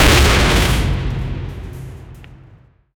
A violent metallic crash as a heavy signpost slams into the ground and a flying body, blending sharp clangs, a deep earth thud, and a fleshy impact. Brutal, fast, chaotic, cinematic — like a devastating blow in an anime fight. 0:03 Created Apr 27, 2025 11:28 AM
a-violent-metallic-crash--qwcyipxd.wav